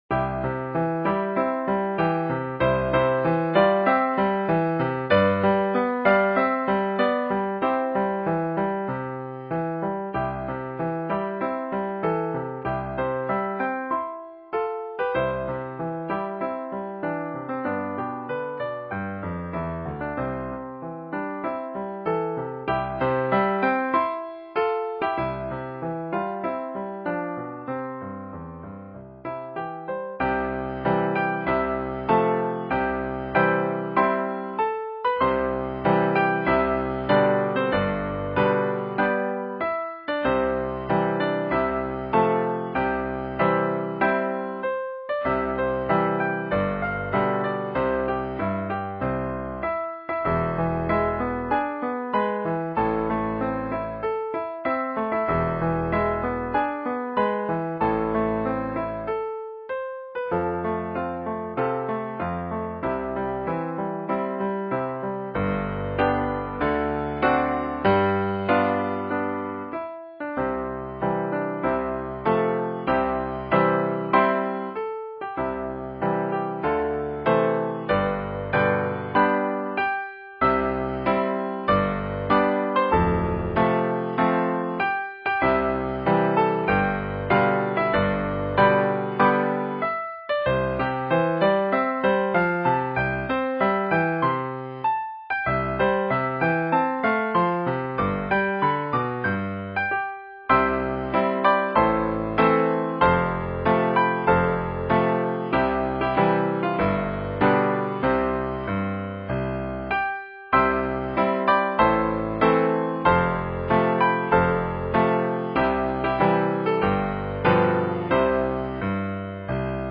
koka-ongen.m4a